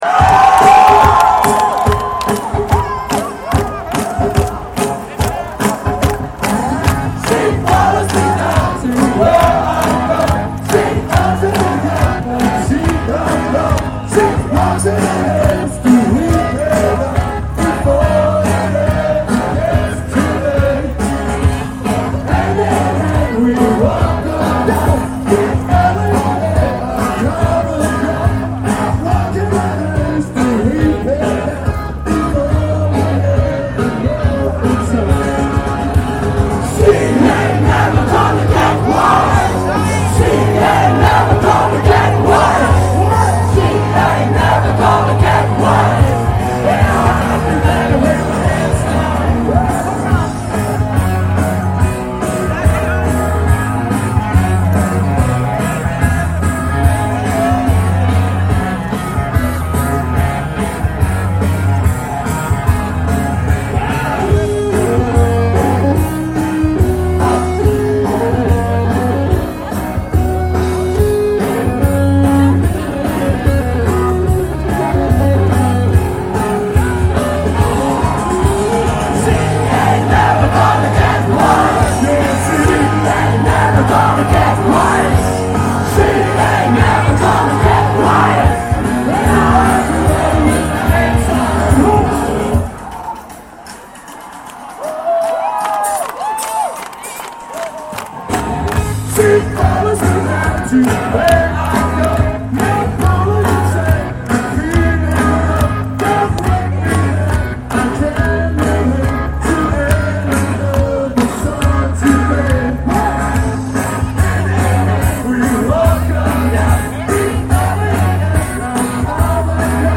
Aufnahmegerät: Sharp IM-DR420H (Mono-Modus)
Mikrofon: Sony ECM-T6 (Mono)